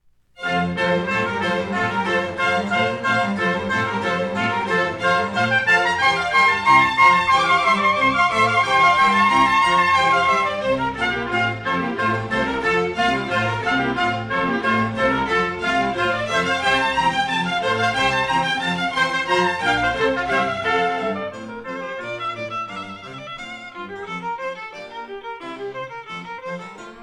trumpet
recorder
violin
harpsichord
1960 stereo recording made by